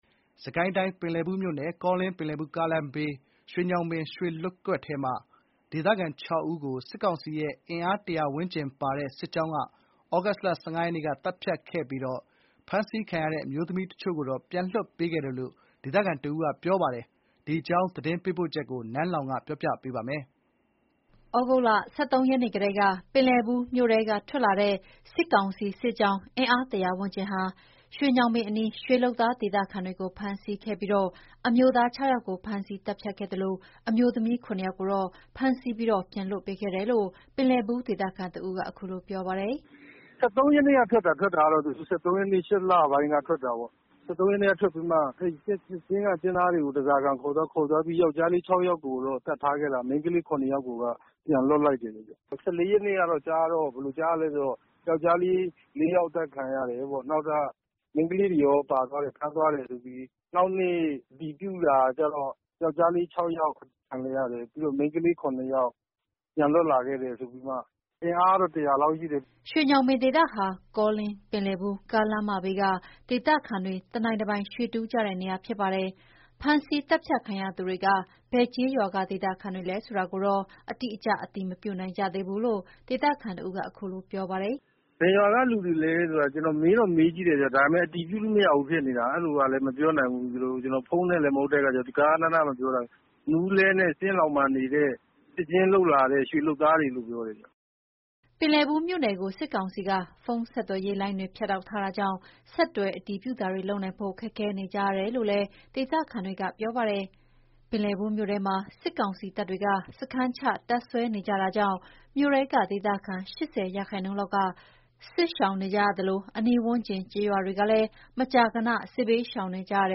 သြဂုတ်လ ၁၃ ရက်နေ့ကတည်းက ပင်လည်ဘူးမြို့ထဲက ထွက်လာတဲ့ စစ်ကောင်စီစစ်ကြောင်း အင်အား ၁၀၀ ဝန်းကျင်ဟာ ရွှေညောင်ပင်အနီးက ရွှေလုပ်သား ဒေသခံတွေကို ဖမ်းဆီးခဲ့ပြီး အမျိုးသား ၆ ယောက်ကို ဖမ်းဆီးသတ်ဖြတ်ခဲ့ပါတယ်။ အမျိုးသမီး ၇ ယောက်ကိုတော့ ဖမ်းဆီးပြီး ပြန်လွှတ်ပေးခဲ့တယ်လို့ ပင်လည်ဘူး ဒေသခံတဦးက ခုလိုပြောပါတယ်။